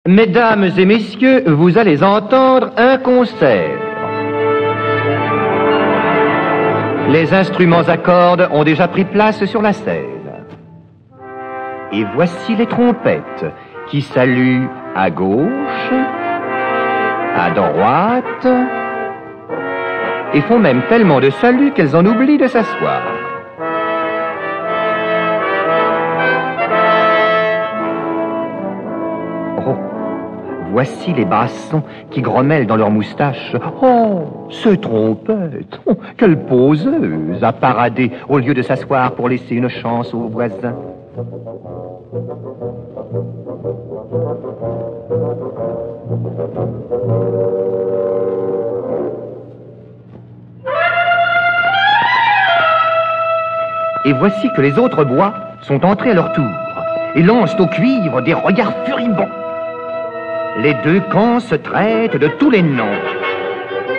Get £0.64 by recommending this book 🛈 Voici un beau conte musical qui permet aux enfants de découvrir un orchestre et son fonctionnement. Chaque instrument à sa place, les bois, les cordes, les cuivres, la batterie, et bien d'autres instruments n'auront plus de secret après cette initiation à la musique orchestrale, spécialement conçue pour les enfants. Enregistrement original de 1958.